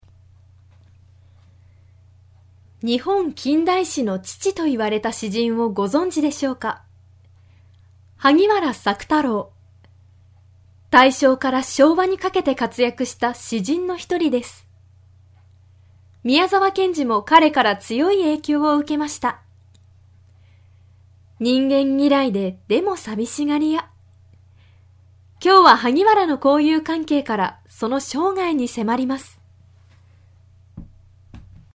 ナレーション | 漫画法人フィールアンドアクト